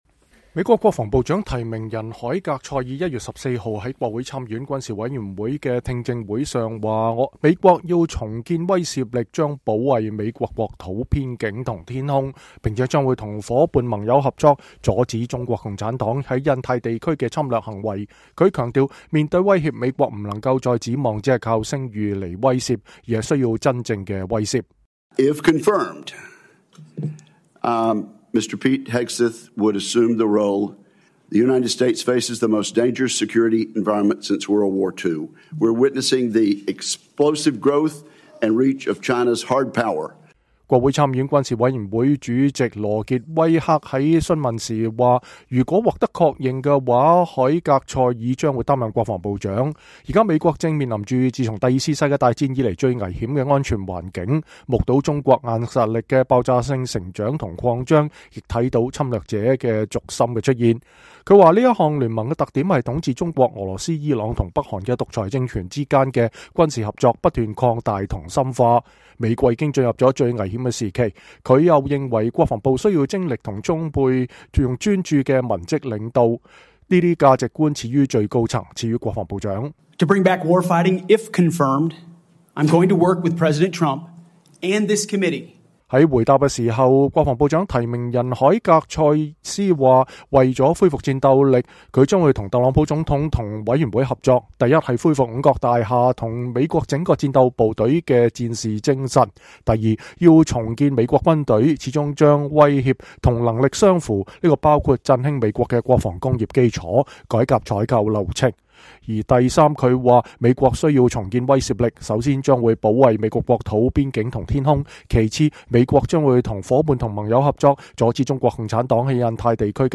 美國國防部長提名人海格塞斯1月14日在參議院軍事委員會的確認聽證會上說，「 我們要重建威懾力。
美國國防部長提名人海格塞斯1月14日在參議院回答議員提問。（美聯社照片）